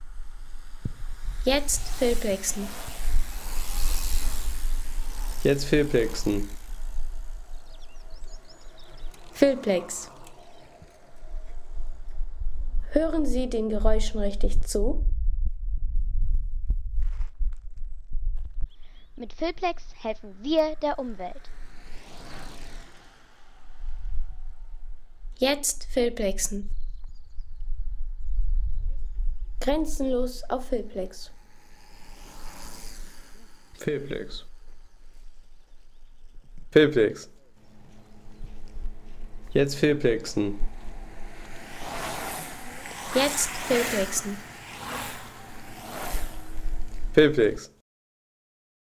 Rennrad-Surren auf dem Brocken
Ein Tag im Harz: Spaziergang und das Surren der Rennräder.